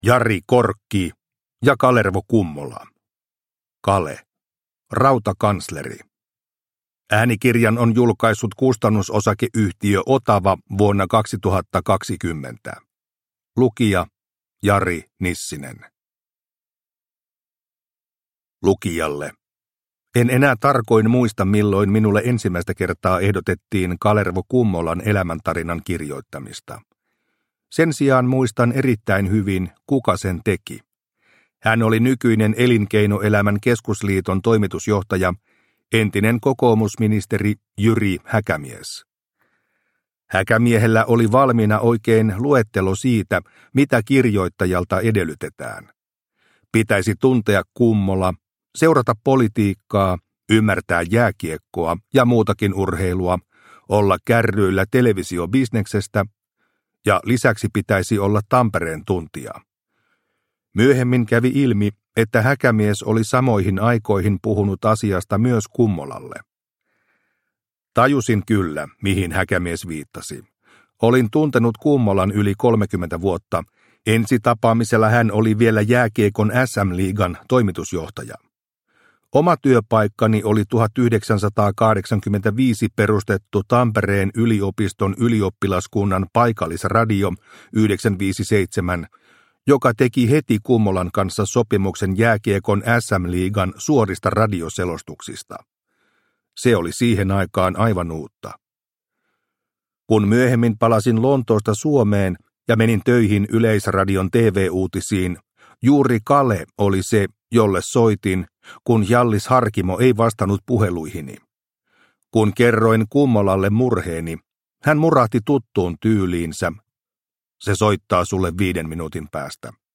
Kale – Ljudbok – Laddas ner